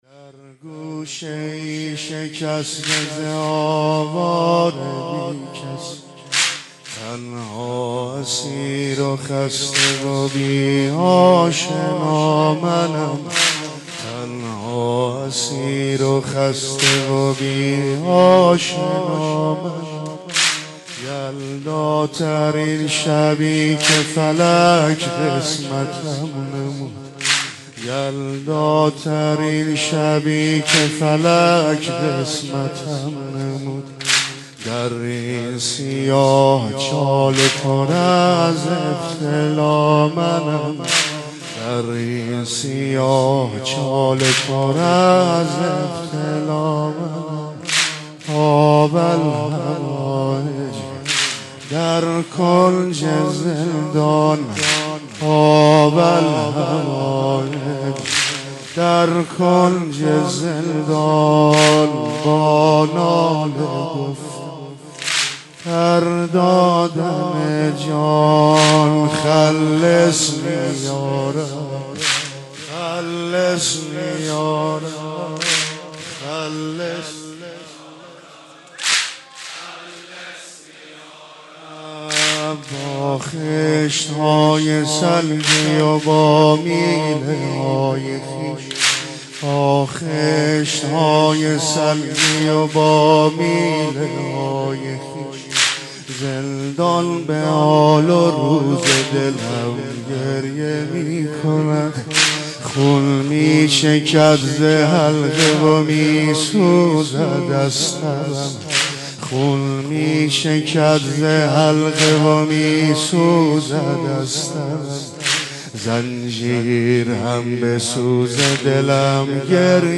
مداحی حاج عبدالرضا هلالی به مناسبت شهادت امام موسی کاظم(ع)